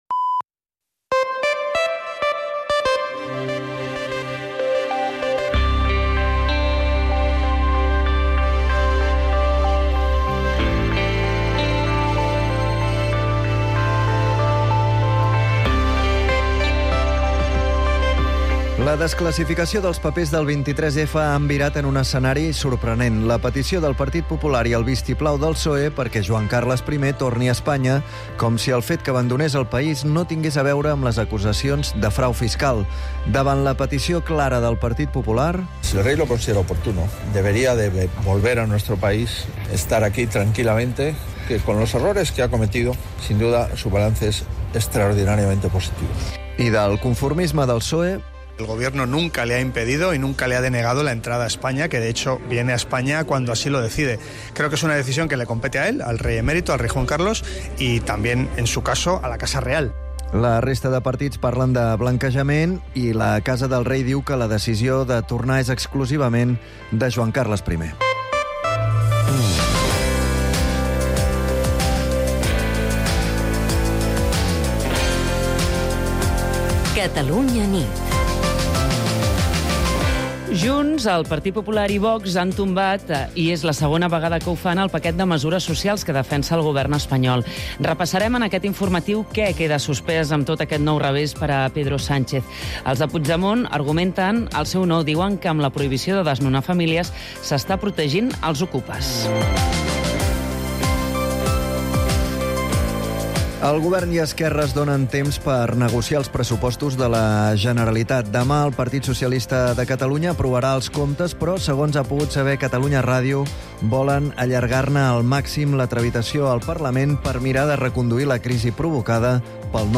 l’informatiu nocturn de Catalunya Ràdio